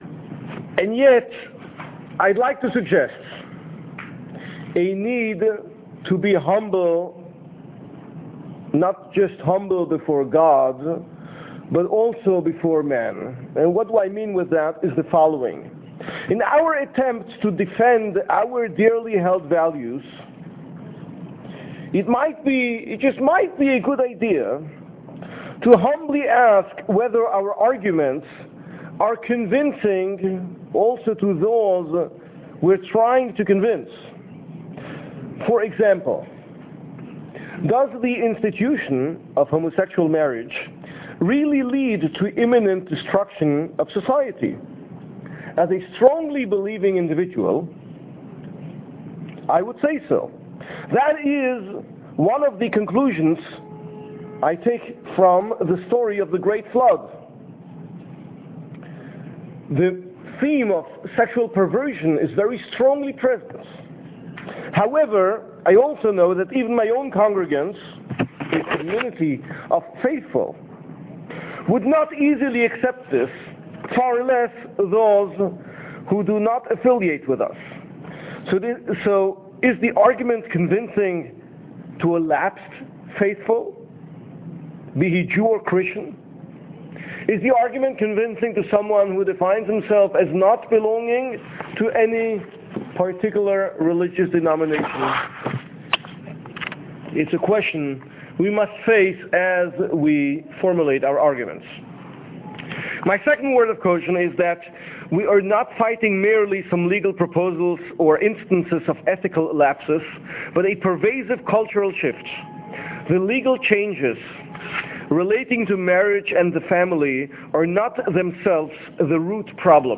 How can we defend traditional family values in the postmodern present, and what do they have to offer to us moderns? A podcast of a talk delivered before an interfaith panel of conservative faith leaders.